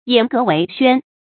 偃革為軒 注音： ㄧㄢˇ ㄍㄜˊ ㄨㄟˊ ㄒㄨㄢ 讀音讀法： 意思解釋： 指停息武備，修治文教。